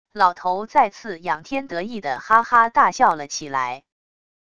老头再次仰天得意地哈哈大笑了起来wav音频